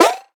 double_jump.ogg